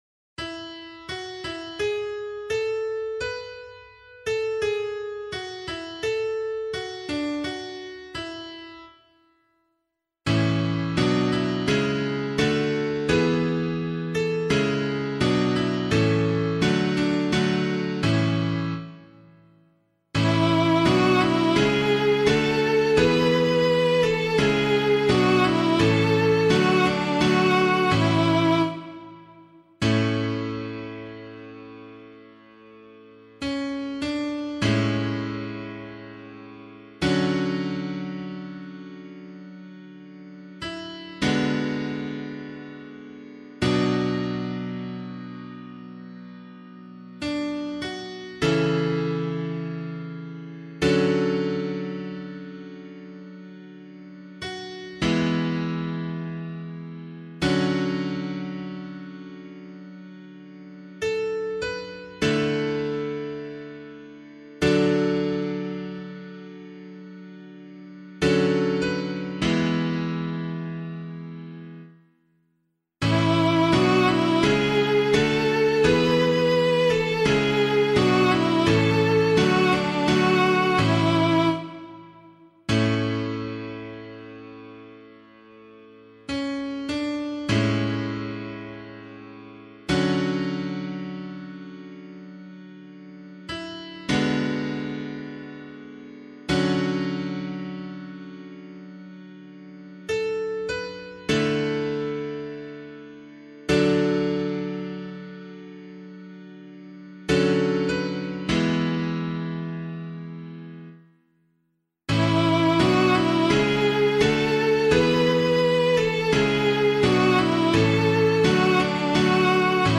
011 Baptism of the Lord Psalm B [Abbey - LiturgyShare + Meinrad 8] - piano.mp3